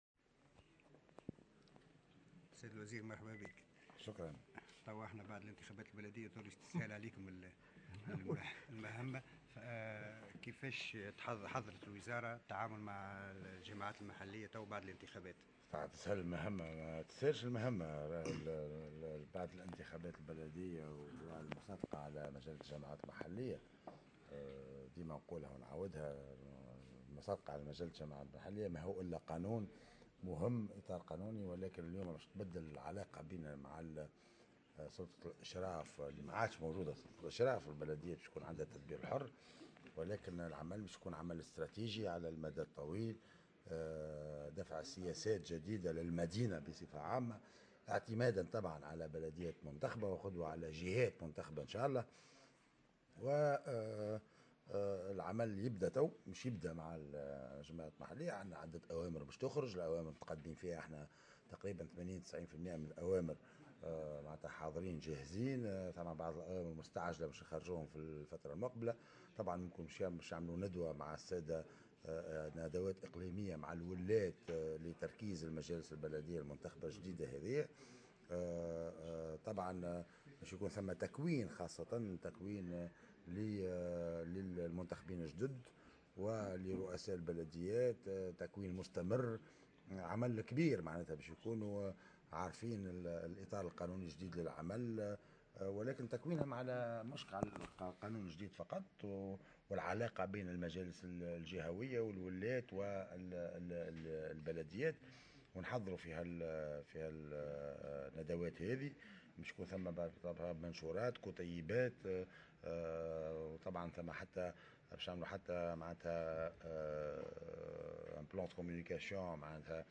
قال وزير الشؤون المحلية والبيئة رياض المؤخر في تصريح لمراسل الجوهرة "اف ام" خلال زيارة أداها إلى ولاية المهدية اليوم الإثنين 14 ماي 2018 إن العلاقة بين الجماعات المحلية وسلطة الإشراف ستتغير بعد الانتخابات البلدية التي جرت وخاصة في ظل وضع مجلة الجماعات المحلية .